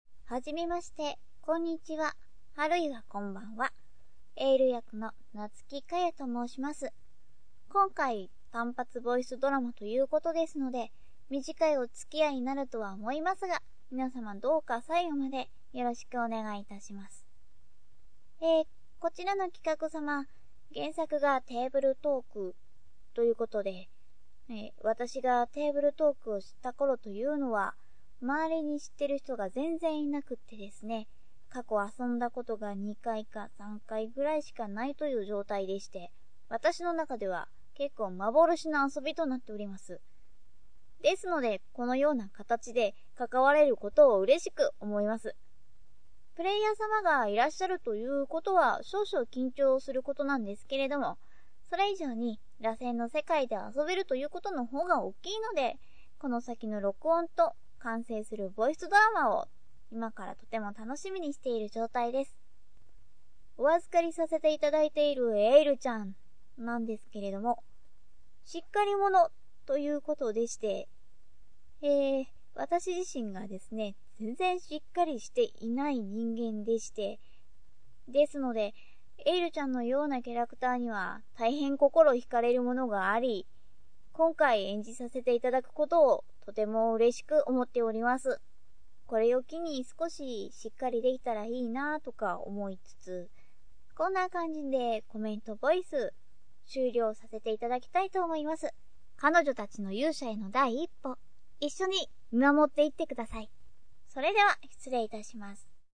声質：中〜高の少女声。ロリ系不可。優等生系のハキハキした喋り方。
コメントボイス